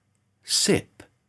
To check whether you’re getting them right, click the top right arrows to see the phonetic transcriptions, and press Play to hear the words said by a native speaker.
/sɪp/
All the flashcards throughout this English pronunciation course have been recorded by native speakers with a Standard British English accent.